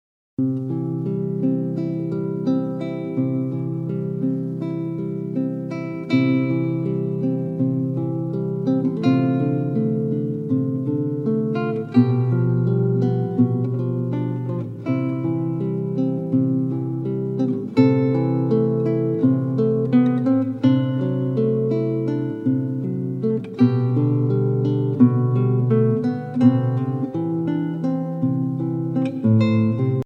Voicing: Guitar Collection